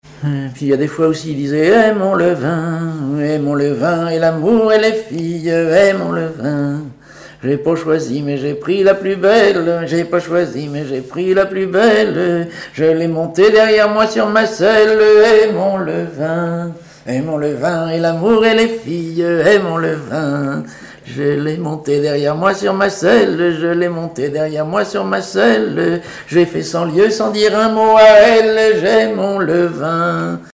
Genre laisse
Cantiques, chants paillards et chansons
Pièce musicale inédite